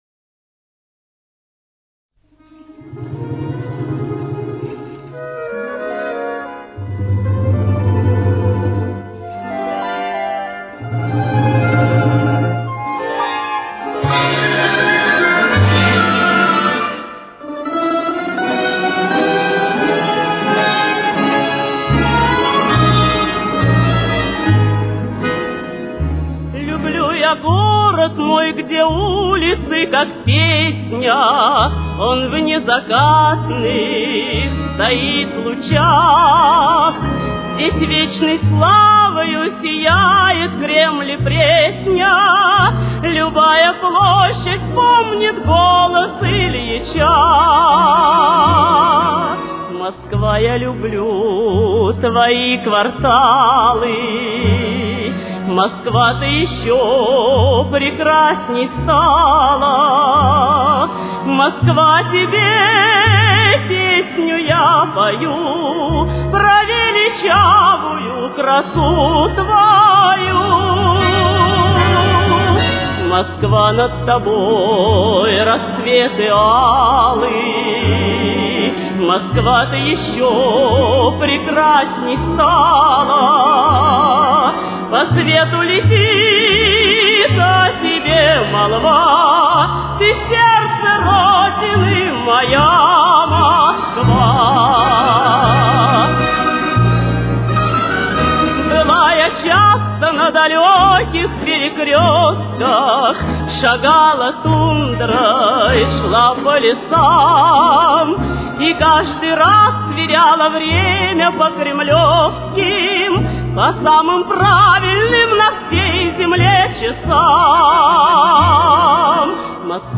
Темп: 84.